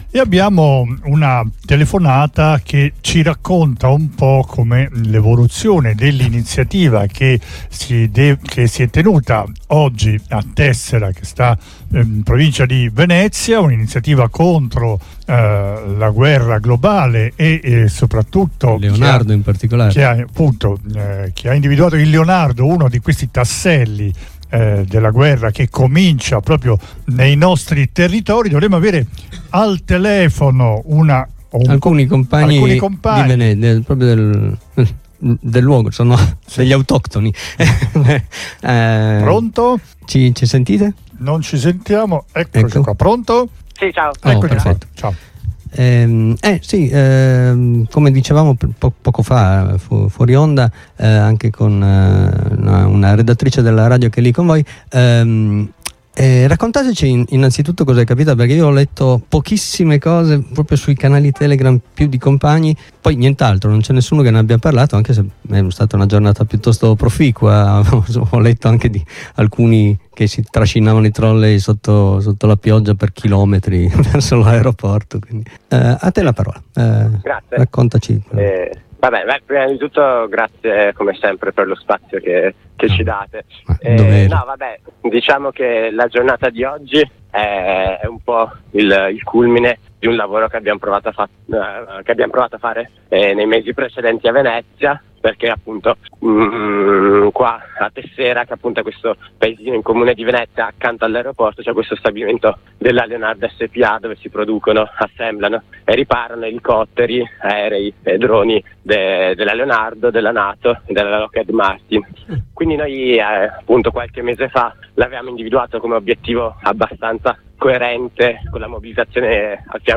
Una prima diretta a Radio Blackout sul blocco contro la sede di Tessera (Venezia) dell’azienda di Stato Leonardo Spa: